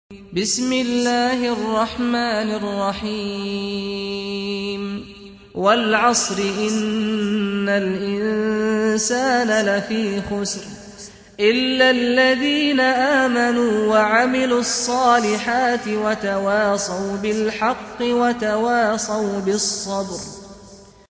سُورَةُ العَصۡرِ بصوت الشيخ سعد الغامدي